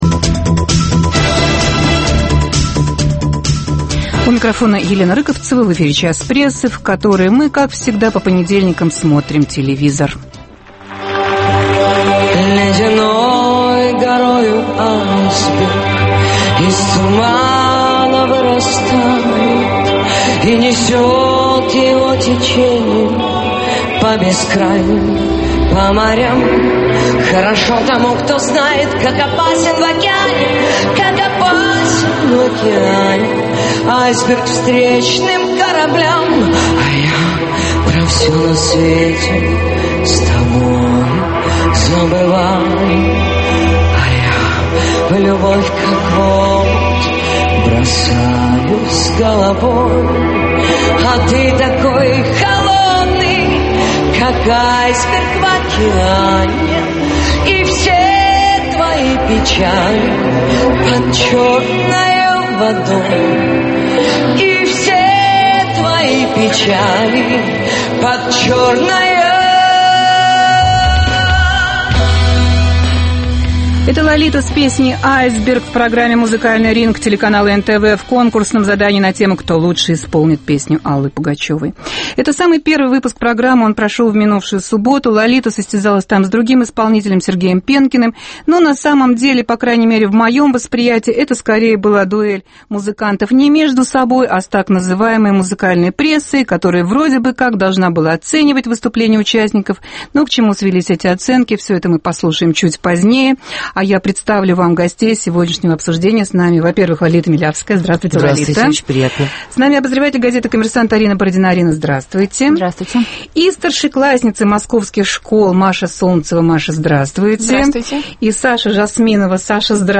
"Музыкальный ринг": вместо поединка исполнителей - дуэль исполнителей с прессой.